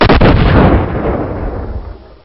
tntbomb.ogg